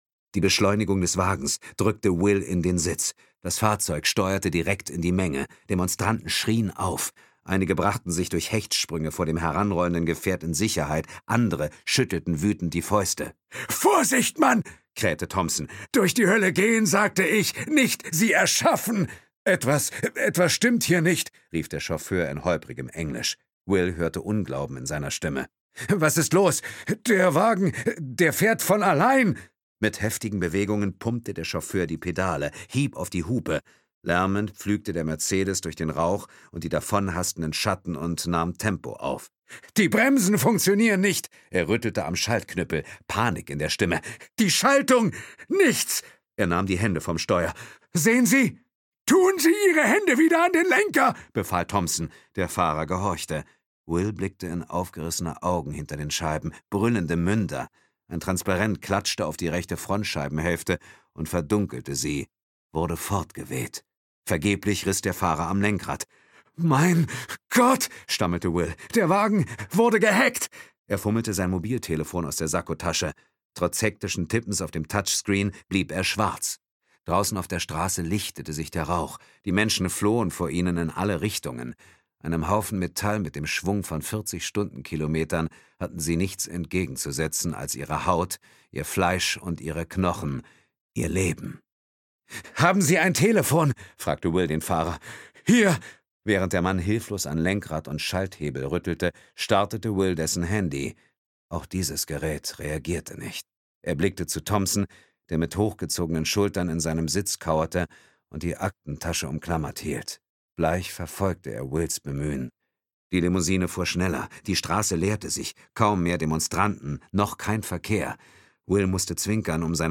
Ukázka z knihy
• InterpretDietmar Wunder